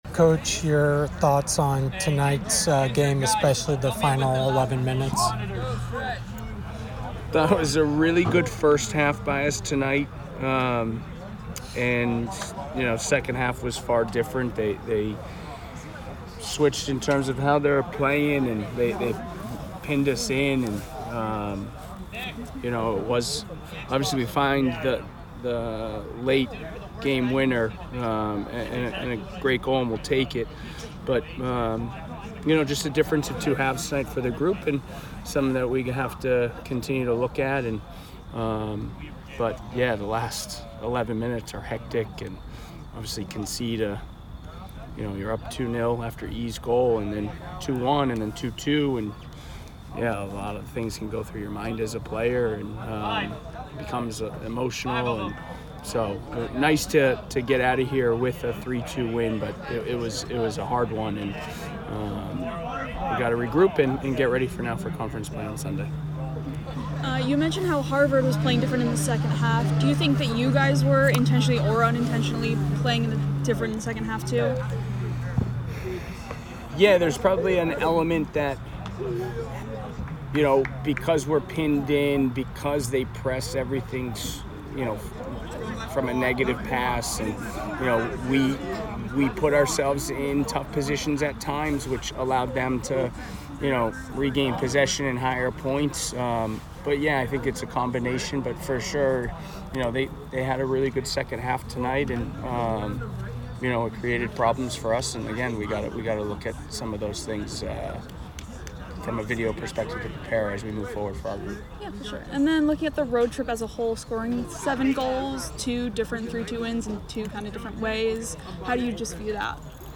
Men's Soccer / Harvard Postgame Interview